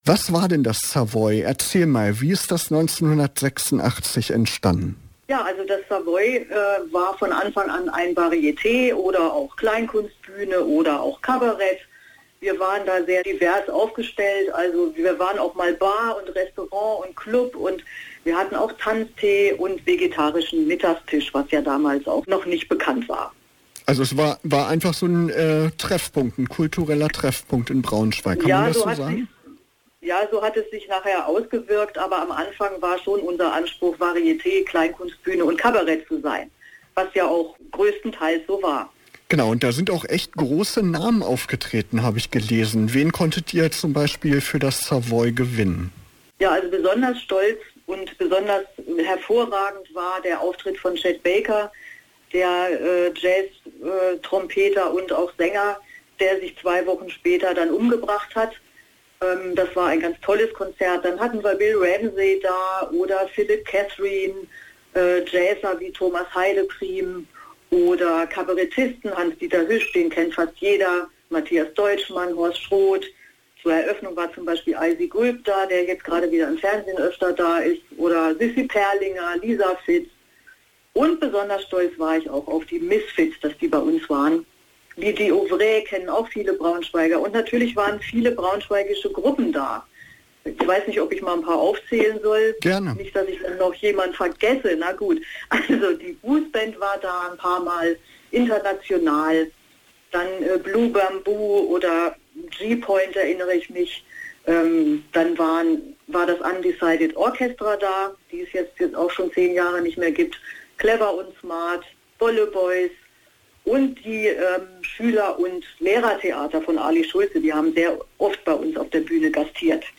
Interview_Savoy_Buch_mh.mp3